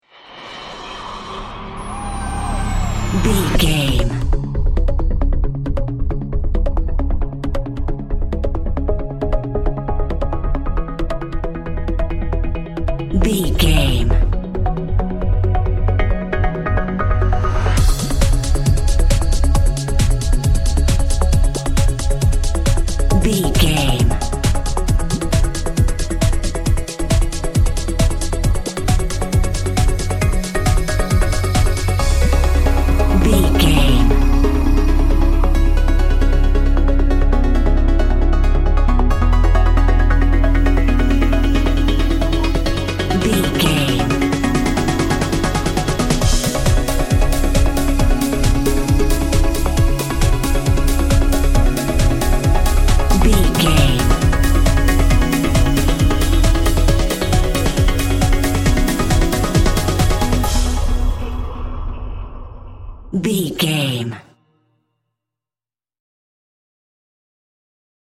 Aeolian/Minor
C#
uplifting
futuristic
hypnotic
dreamy
smooth
synthesiser
drum machine
house
techno
electro house
synth lead
synth bass